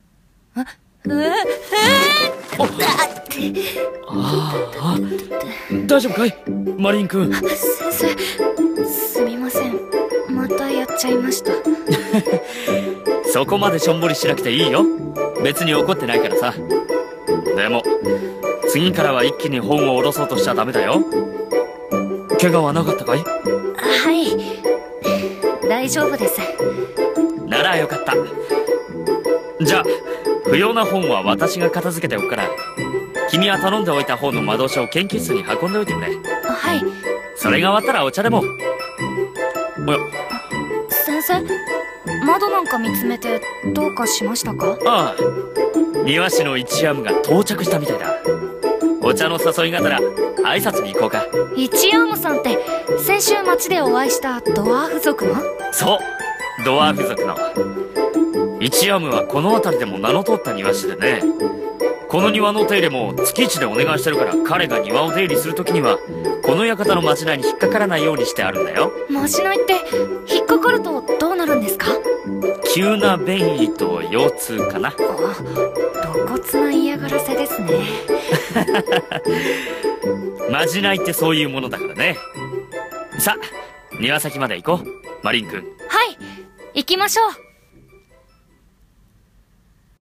【声劇】魔法日和と、庭の大きな館